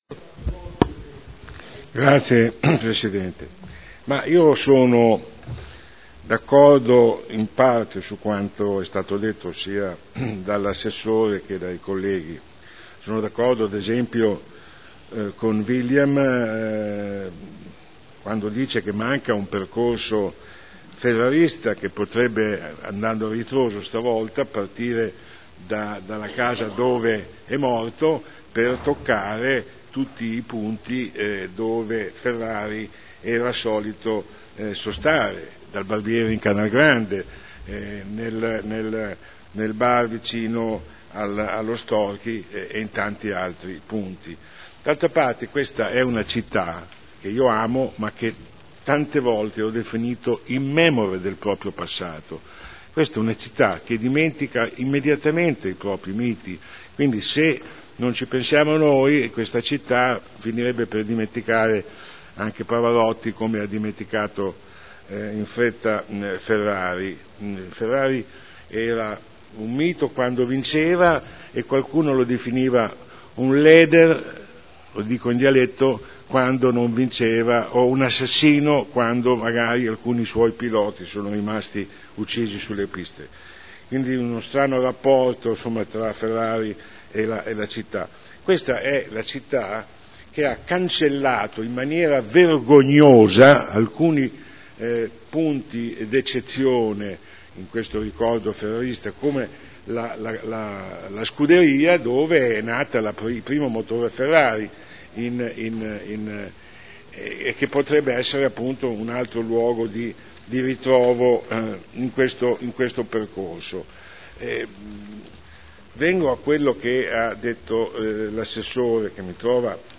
Sandro Bellei — Sito Audio Consiglio Comunale
Seduta del 09/01/2012. Dibattito su Interrogazione del consigliere Bellei (PdL) avente per oggetto: “Museo Enzo Ferrari” (presentata il 9 settembre 2011 - in trattazione il 9.1.2012) trasformata in interpellaznza su richiesta del Consigliere Celloni.